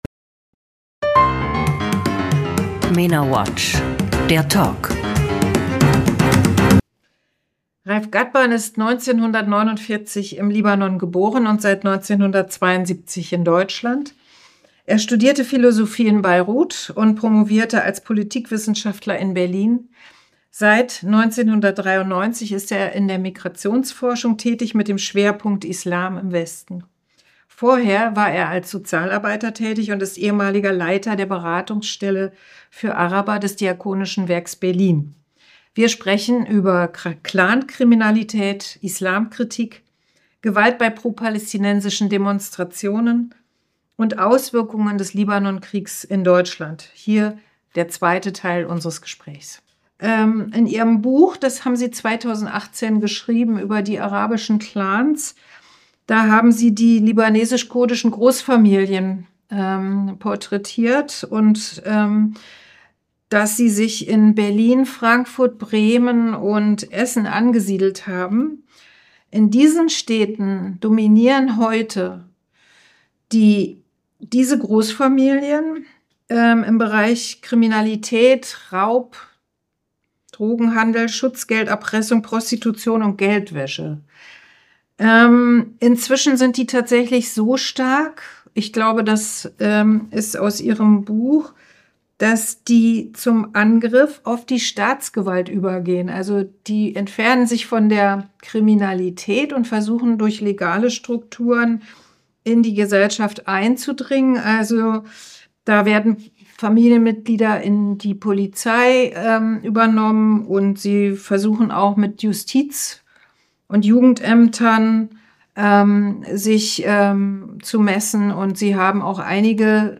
(Ein Gespräch in drei Teilen, dessen nächste Folge in einer Woche hier erscheint.)